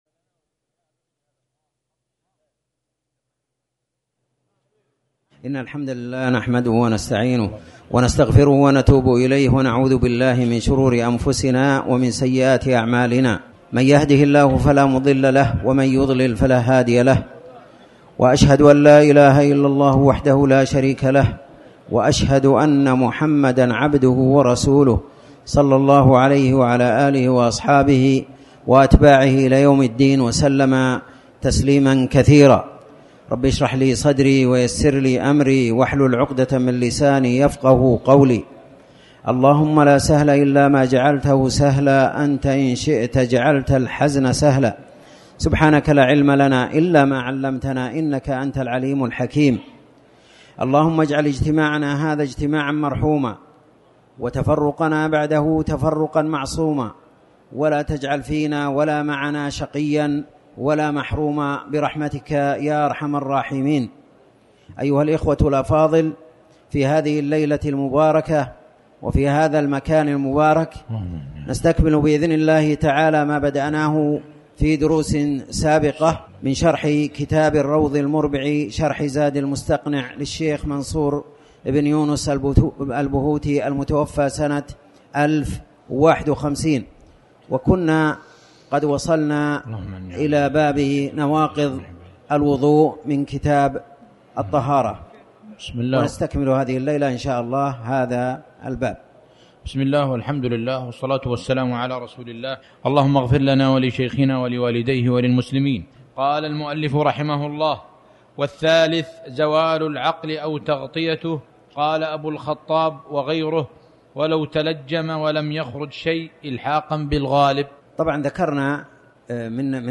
تاريخ النشر ٢٧ صفر ١٤٤٠ هـ المكان: المسجد الحرام الشيخ